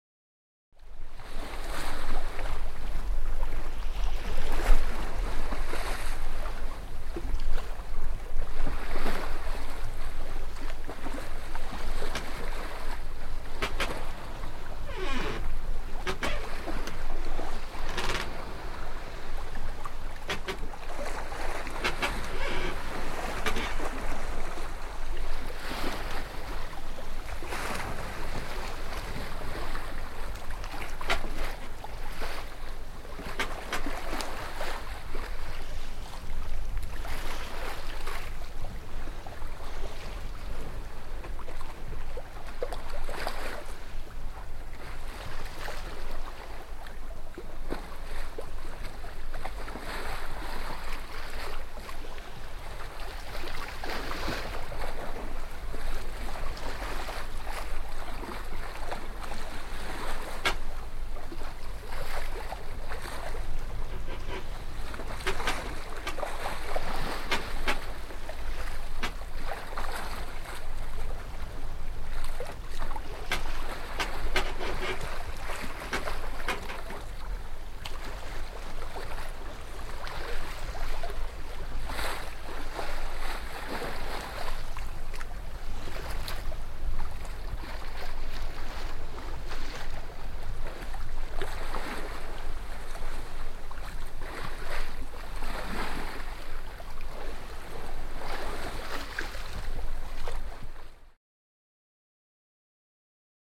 Звуки моря, волн
Звуки открытого моря под парусами корабля